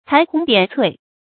裁红点翠 cái hóng diǎn cuì 成语解释 ①采摘花卉。②比喻选择华丽的辞藻。
成语繁体 裁紅點翠 成语简拼 cgdc 成语注音 ㄘㄞˊ ㄏㄨㄥˊ ㄉㄧㄢˇ ㄘㄨㄟˋ 常用程度 一般成语 感情色彩 中性成语 成语用法 作谓语、宾语；用于写作等 成语结构 联合式成语 产生年代 古代成语 成语例子 〖示例〗乐府初翻新谱，漫 裁红点翠 ，闲题金缕。